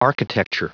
Prononciation du mot architecture en anglais (fichier audio)
Prononciation du mot : architecture